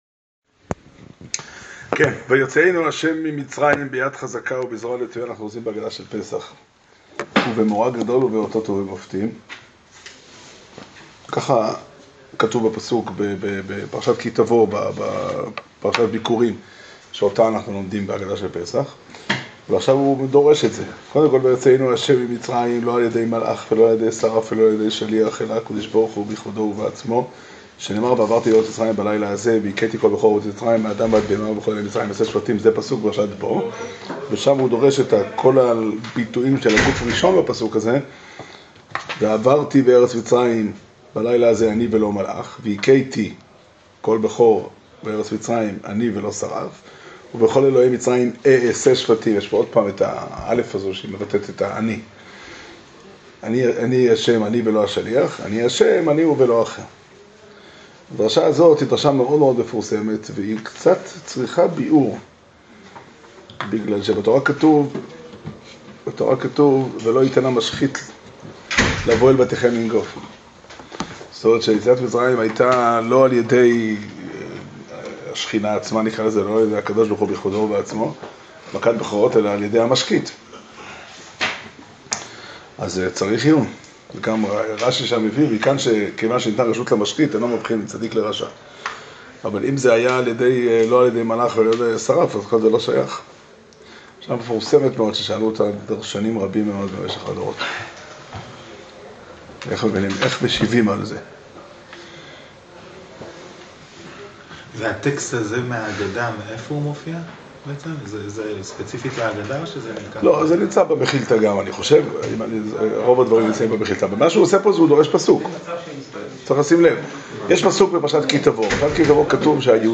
שיעור שנמסר בבית המדרש 'פתחי עולם' בתאריך י"ח אדר ב' תשע"ט